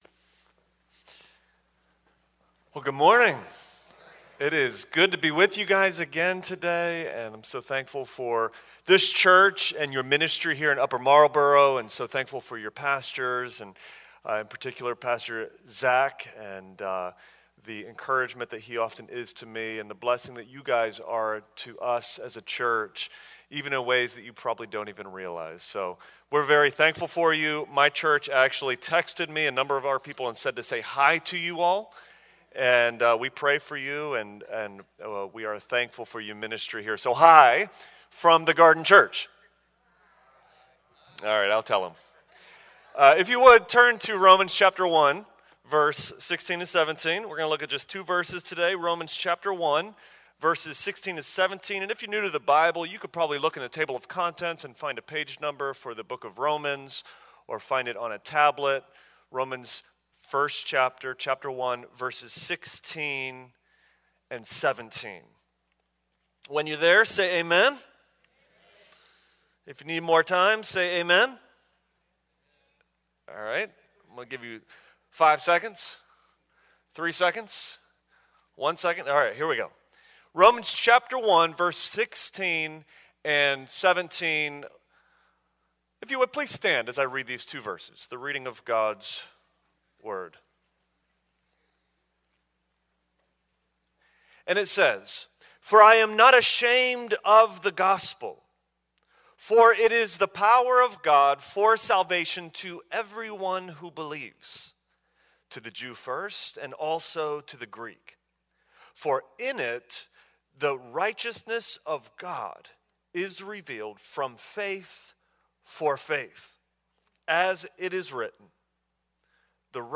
A message from the series "Friends of FBCUM."